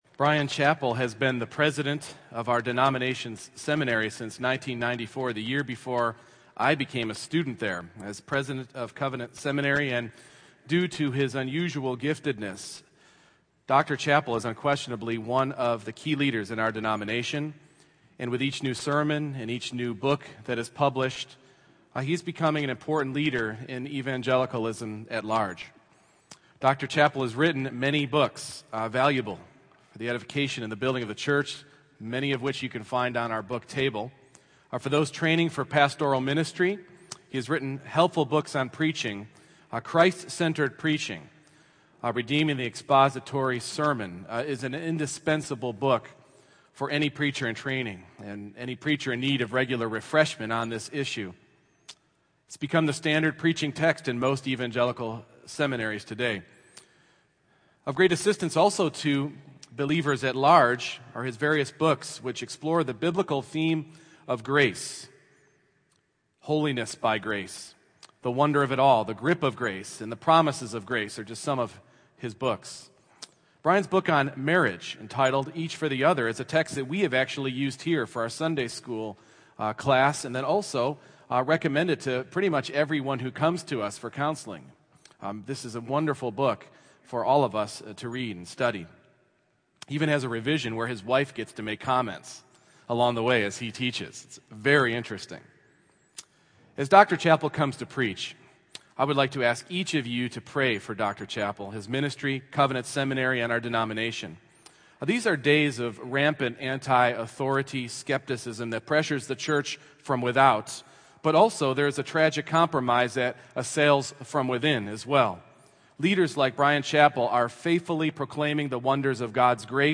No Series Passage: Deuteronomy 1:21-36 Service Type: Morning Worship Download Files Bulletin « A Slave to the Lender